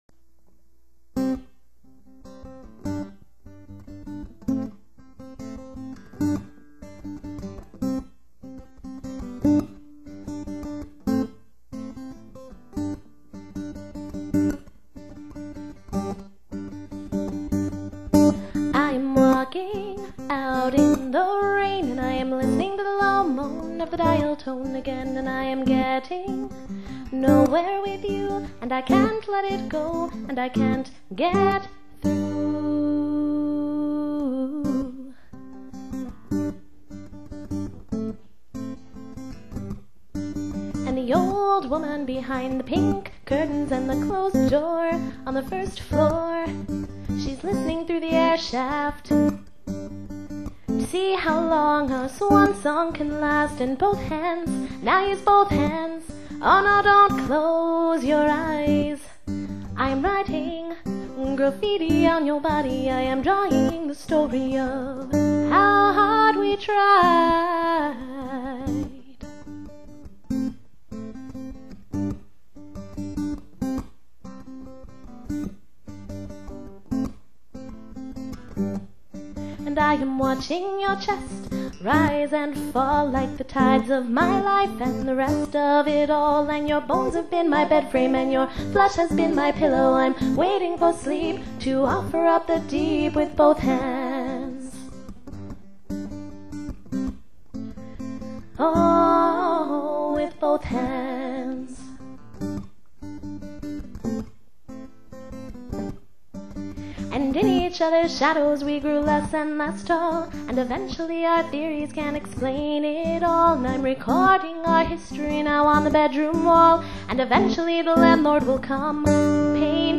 guitar
All songs recorded in May 2002 in Dallas, Texas